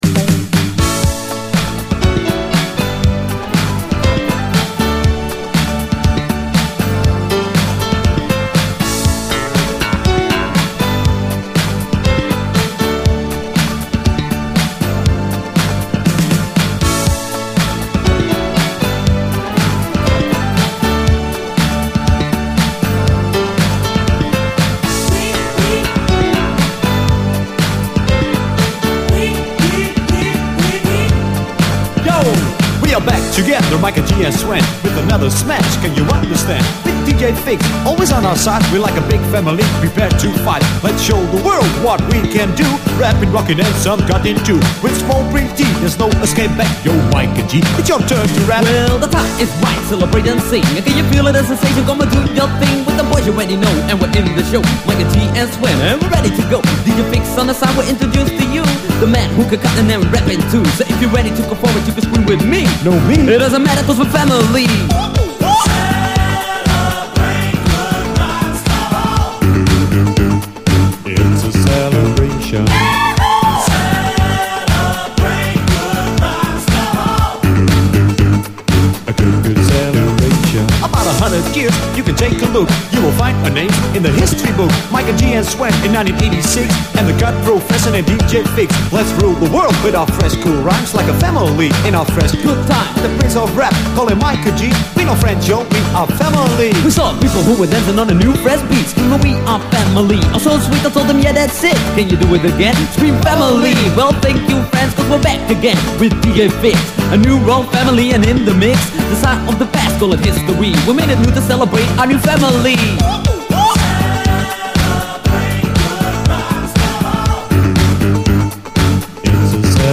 DISCO, HIPHOP
ユーロ産パーティー・ディスコ・ラップ！